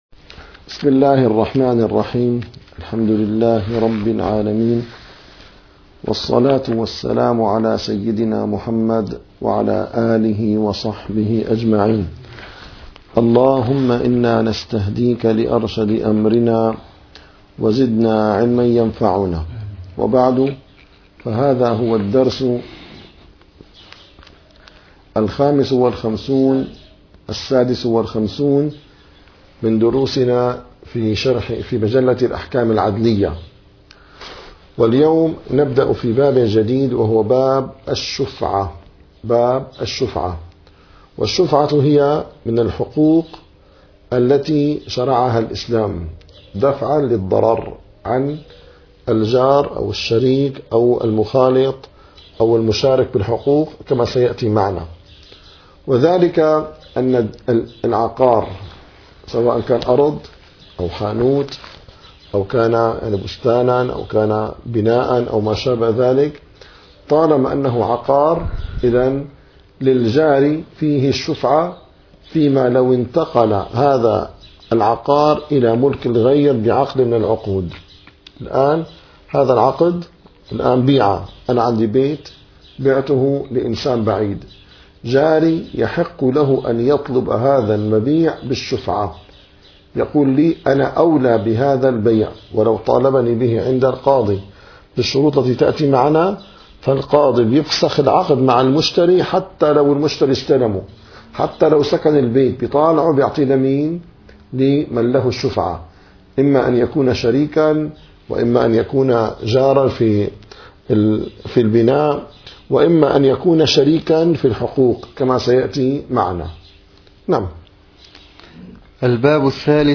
- الدروس العلمية - الفقه الحنفي - مجلة الأحكام العادلية - 56- مادة 1008 أسباب الشفعة ثلاثة